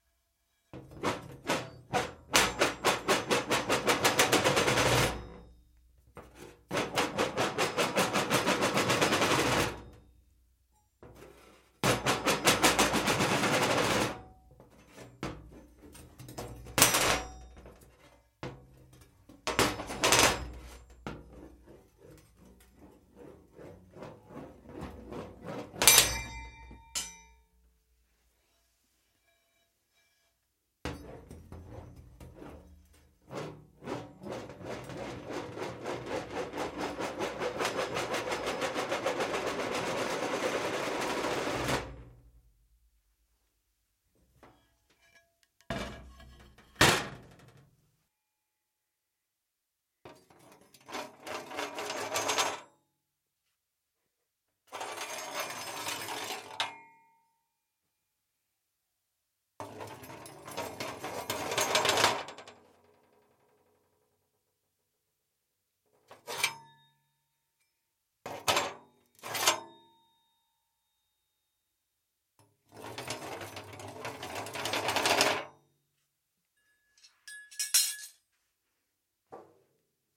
金属、木材和拉链 " METAL SFX FOLEY, 螺丝和钉子摇晃，拿起，处理
描述：我们在泰国北部的Digital Mixes工作室录制了一些基于金属的声音，我们正在为我们的声音数据库做准备，但我们想与大家分享。
声道立体声